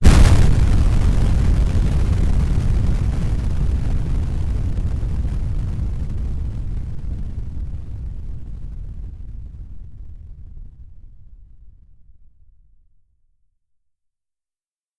描述：我在audacity中创建的一个廉价的声音爆炸。
Tag: 手榴弹 炸药 炸药 战斗 装备部队 爆炸 战争 价格便宜 军队 爆炸